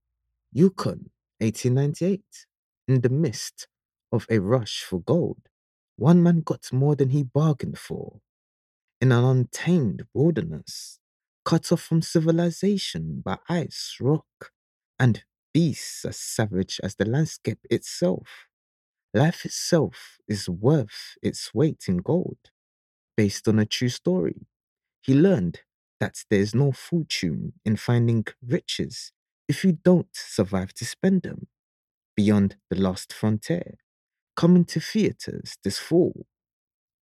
Hire Movie Trailer Voice Over Actors
English (Caribbean)
Yng Adult (18-29) | Adult (30-50)